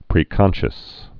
(prē-kŏnshəs)